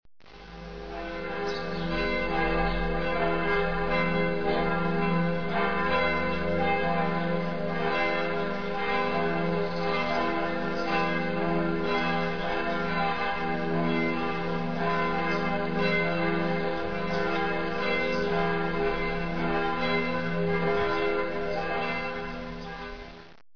bell-neu.mp3